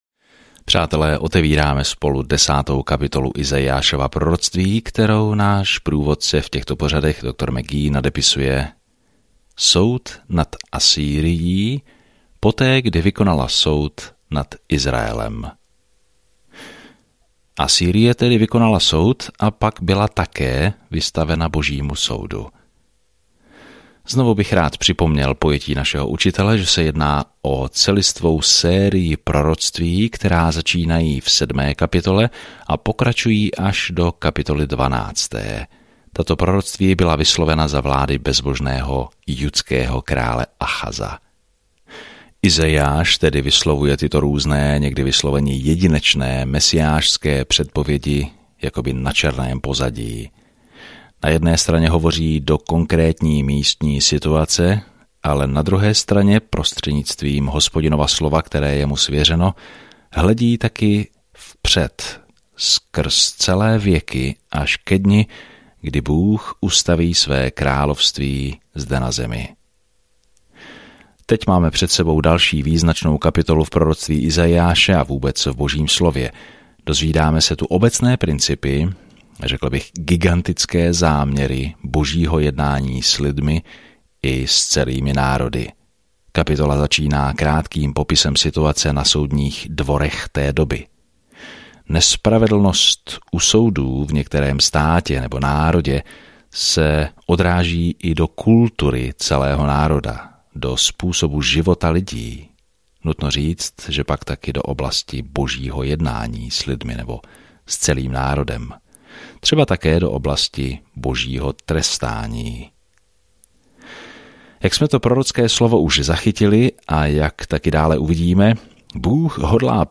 Písmo Izaiáš 10:1-12 Den 15 Začít tento plán Den 17 O tomto plánu Izajáš, nazývaný „páté evangelium“, popisuje přicházejícího krále a služebníka, který „ponese hříchy mnohých“ v temné době, kdy Judu dostihnou političtí nepřátelé. Denně procházejte Izajášem a poslouchejte audiostudii a čtěte vybrané verše z Božího slova.